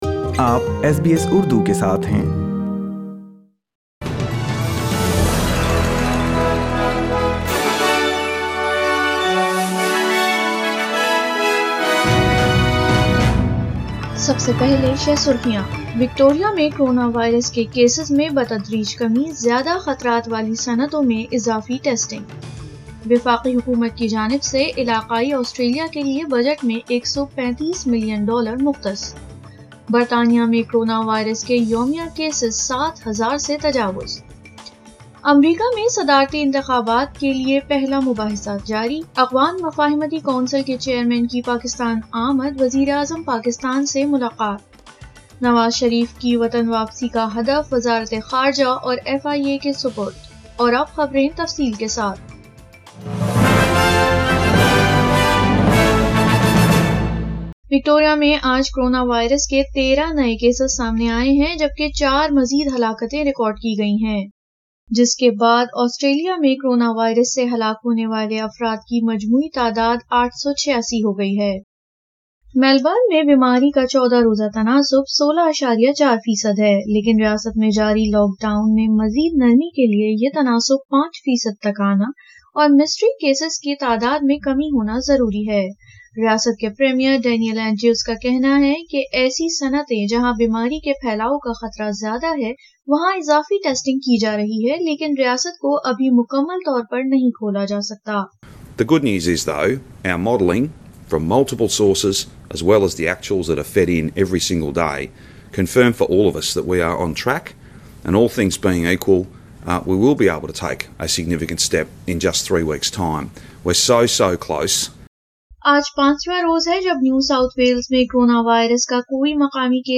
اردو خبریں بدھ 30 ستمبر 2020